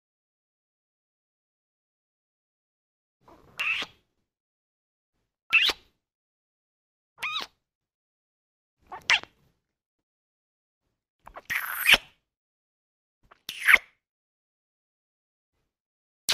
دانلود آهنگ بوس 3 از افکت صوتی انسان و موجودات زنده
دانلود صدای بوس 3 از ساعد نیوز با لینک مستقیم و کیفیت بالا
جلوه های صوتی